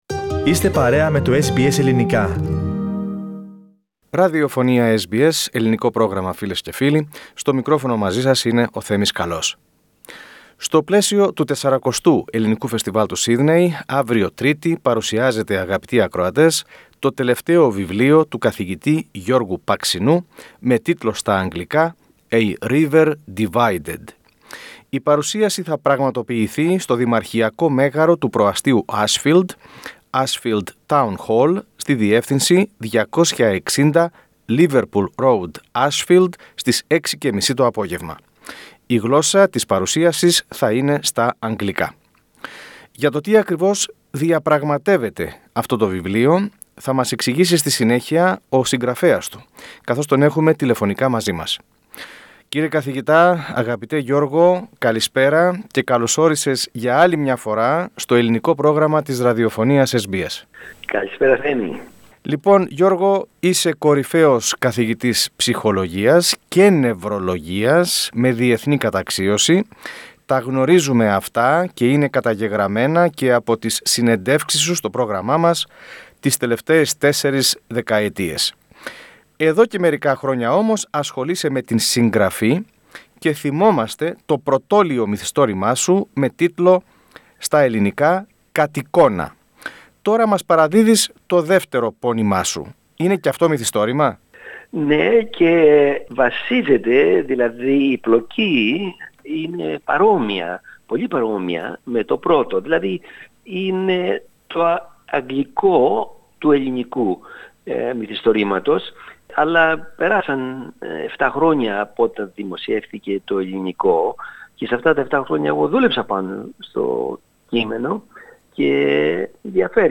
Στην συνέντευξη που παραχώρησε στο SBS Greek, o Καθηγητής Παξινός αναφέρεται διεξοδικά στο τί τον οδήγησε να γράψει το μυθιστόρημά του, το οποίο έχει για ήρωες δύο πρόσωπα που κλωνοποιήθηκαν με γενετικό υλικό του Ιησού Χριστού.